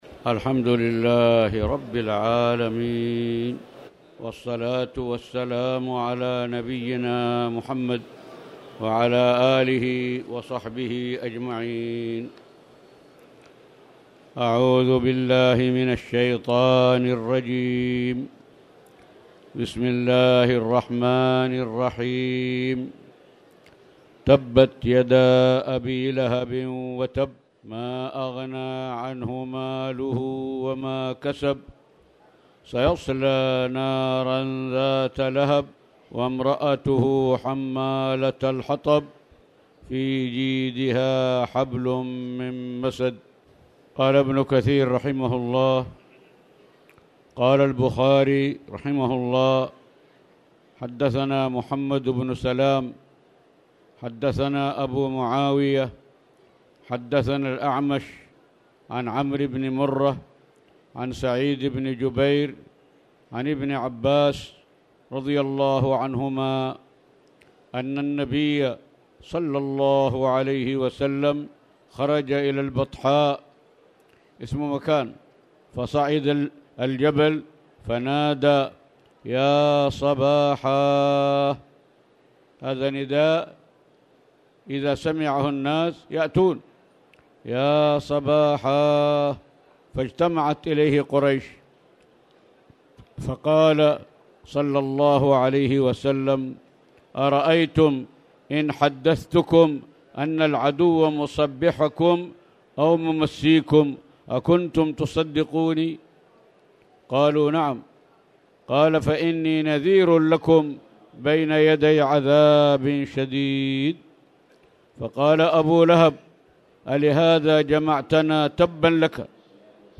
تاريخ النشر ٢٨ رمضان ١٤٣٧ هـ المكان: المسجد الحرام الشيخ